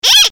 clock10.ogg